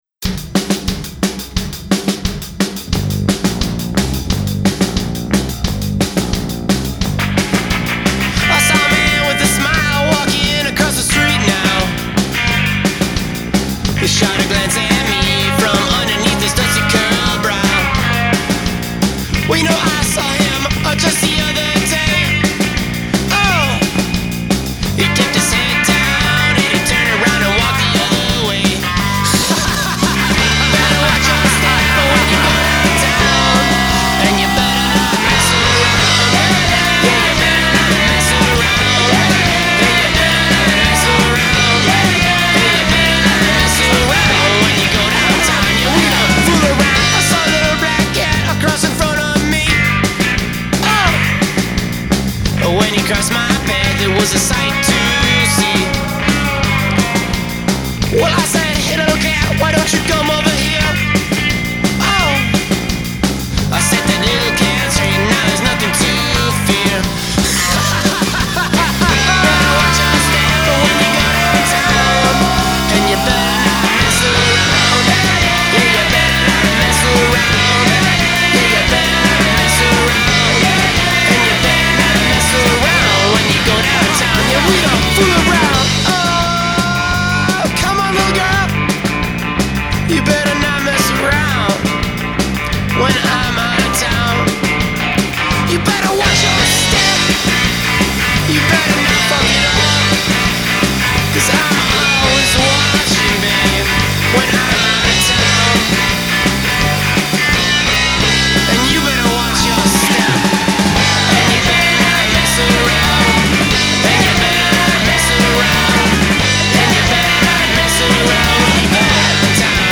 authentic pop-fuelled, garage rock rave-ups